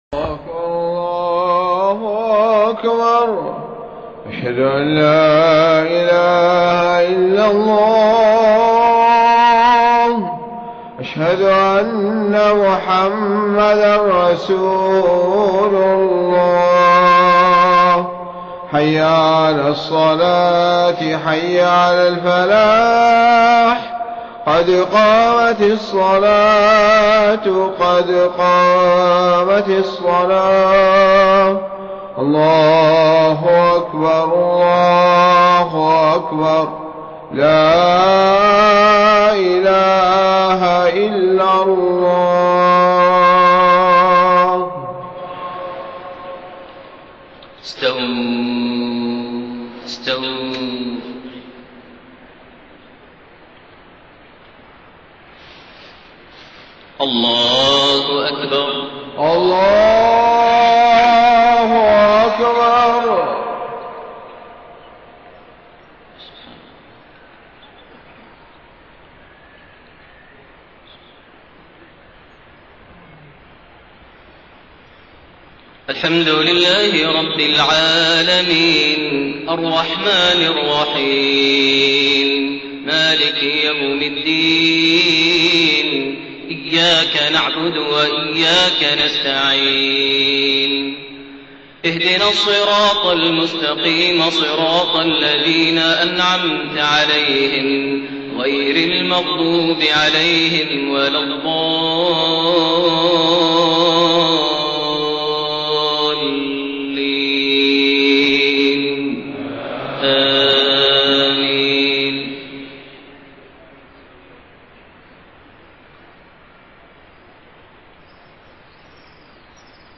صلاة المغرب 9-6-1432 | من سورة الزمر 53-61 > 1432 هـ > الفروض - تلاوات ماهر المعيقلي